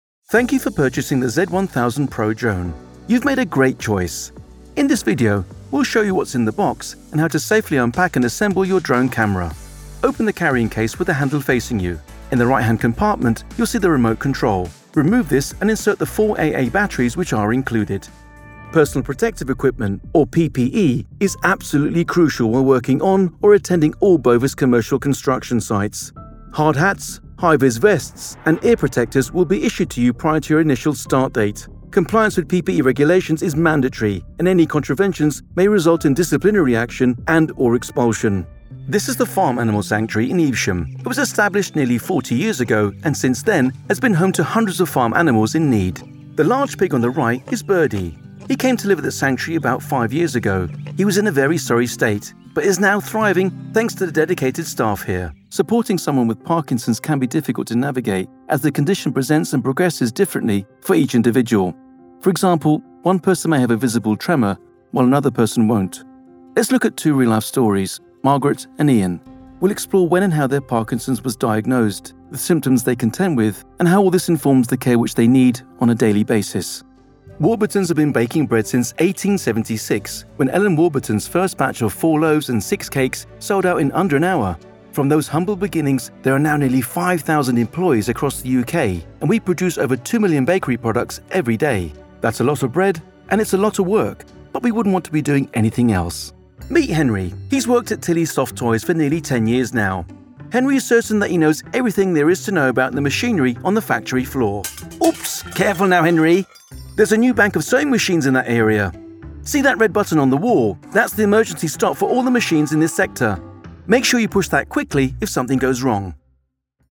I've a versatile voice. Can I do dynamic teenagers voices or voices elegants for corporates products. All records with the profesional quality of my studio....
Spain (Castilian) Teenager